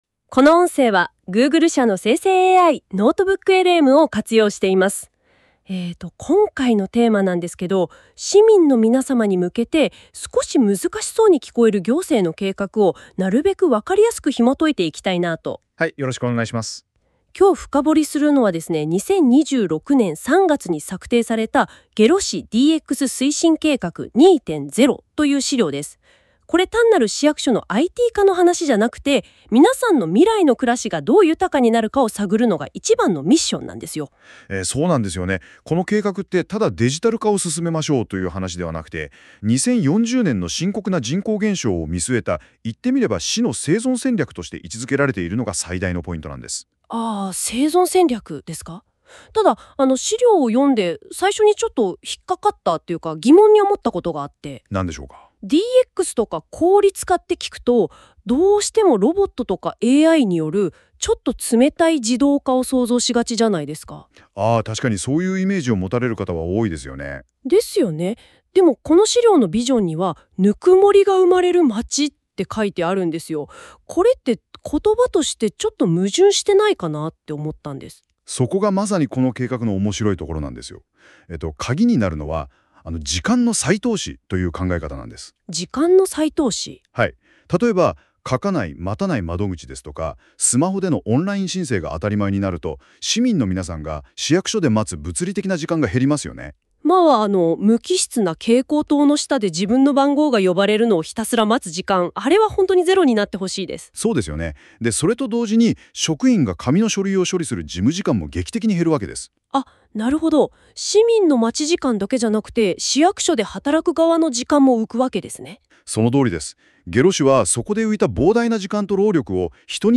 当ページの内容を生成AIによって作成した音声概要を聞くことができます。